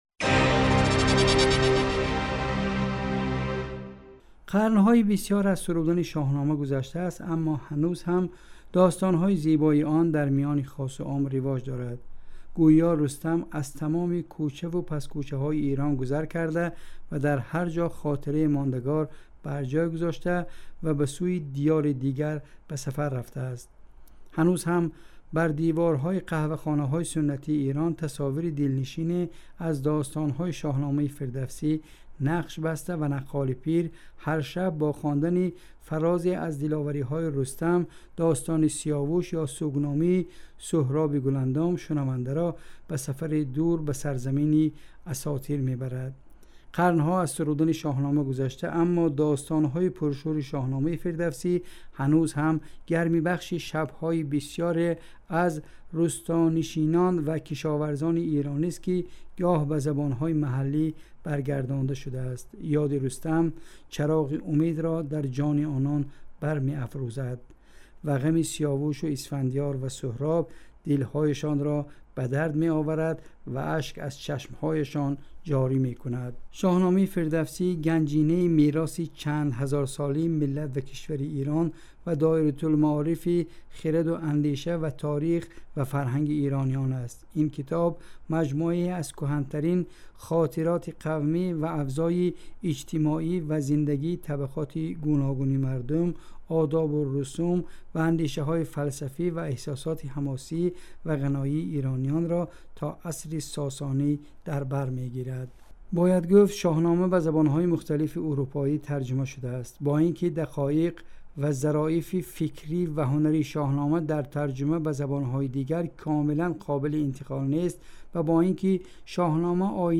Таъсири Фирдавсӣ ба забону фарҳанги порсӣ (гузориши вижа)